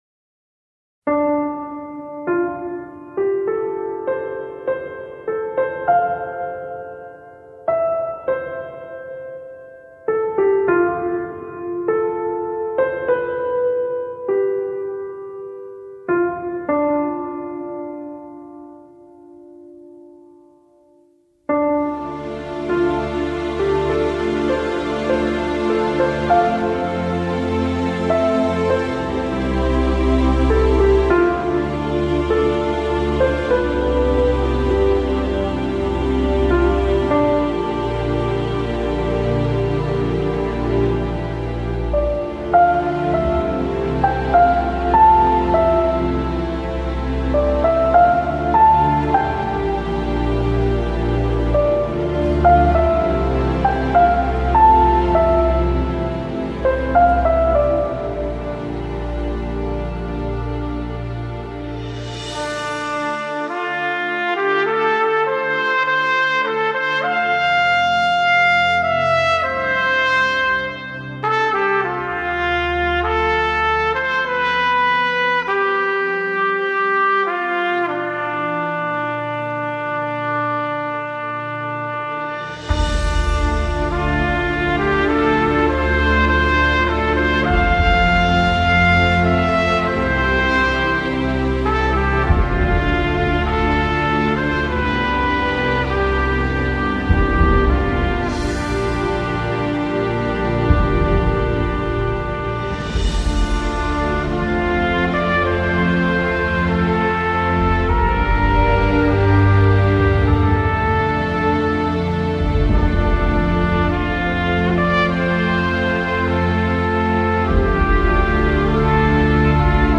音乐类型：O.S.T
简单的钢琴声，哀怨缠绵，慢慢的，声势变得宏大起来